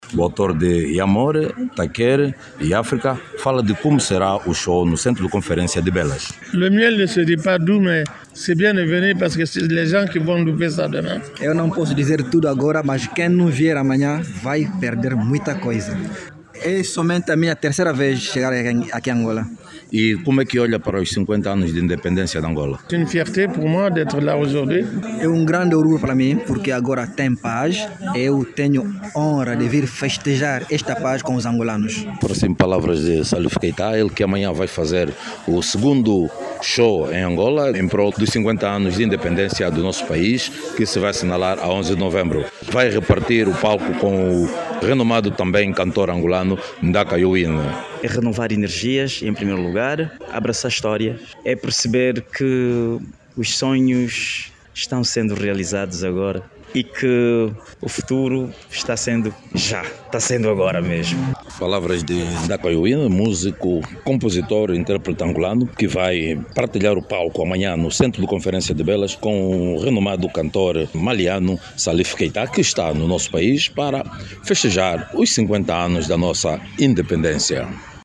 O Conceituado cantor maliano Salif Keitá, vai realizar o seu segundo show hoje, quarta-feira, 17, no Centro de Conferência de Belas, em Luanda, depois de tê-lo feito na província do Huambo, em alusão aos 50 anos de independência. Saiba mais dados no áudio abaixo com o repórter